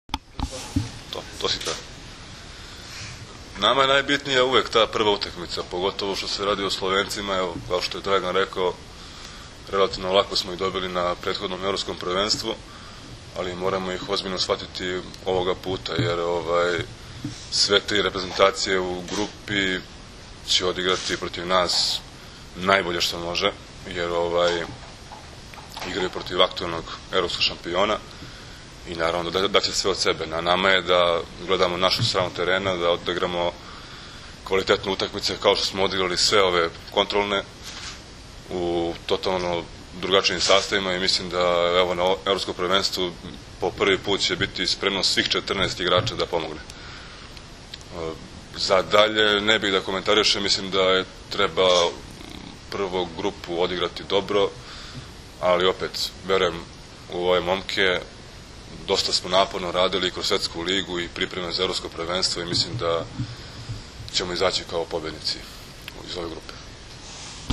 IZJAVA VLADE PETKOVIĆA